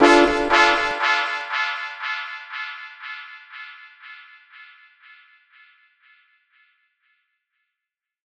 Dub Horn Delay.wav